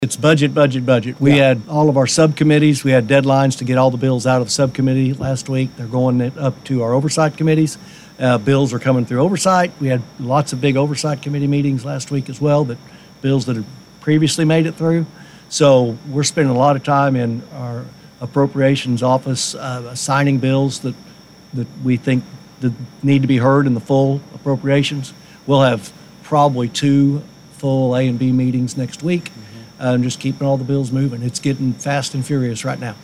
Following the forum, Sen. Daniels, and Reps. Kane and Strom joined KWON in studio for Capitol Call powered by Phillips 66.
John B Kane on Next Week 2-28.mp3